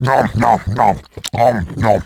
nomnomnom.mp3 (83.67 KB)